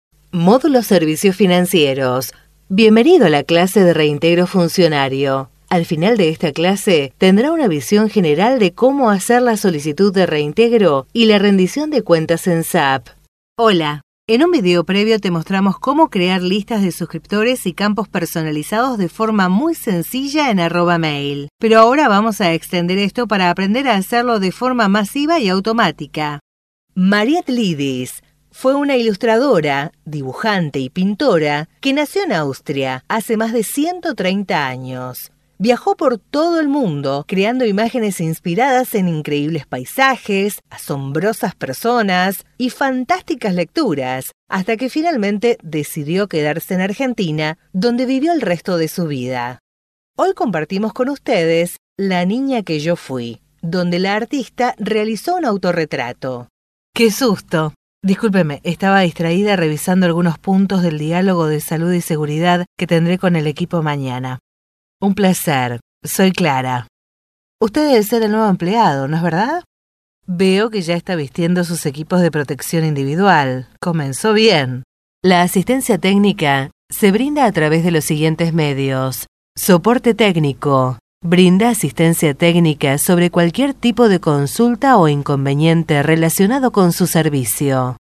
Videos tutoriales
Voz en off para videos paso a paso, tutoriales, educación.